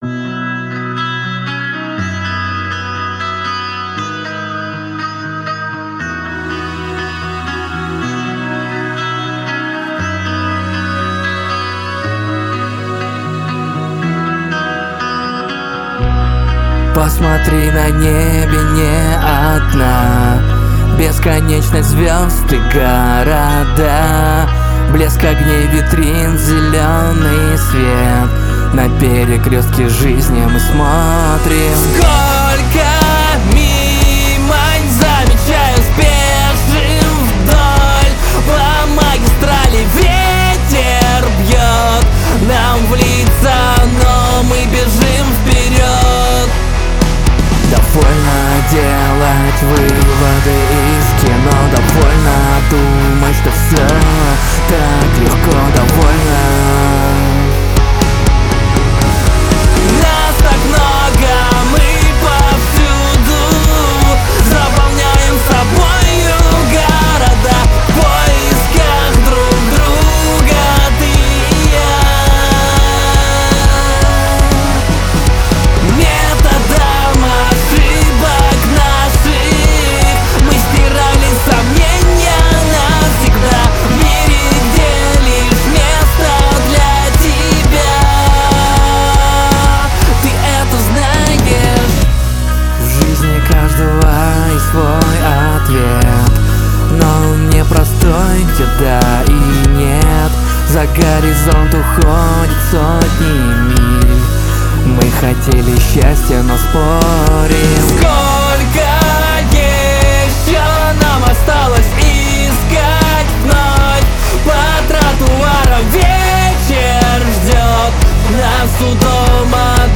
Релизнул трек на стриминги, в итоге звук немного изменился, но конкретно очень сильно изменился звук рабочего барабана. Как вы можете слышать в оригинале он плотнее и приятнее, после стримингов стал каким то гудящим. хрустящим, и дребезжащим.